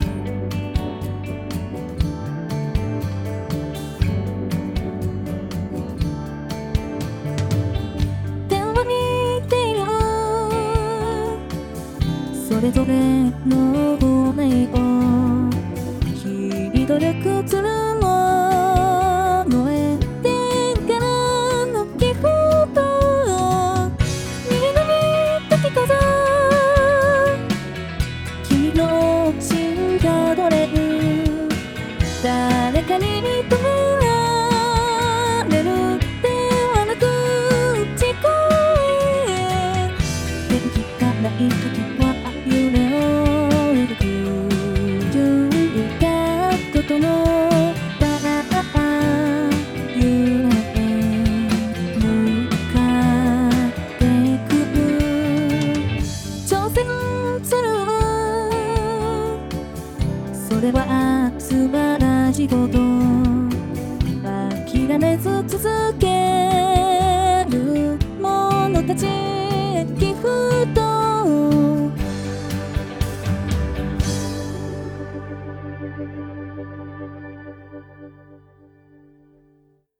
[歌]
※Band-in-a-Boxによる自動作曲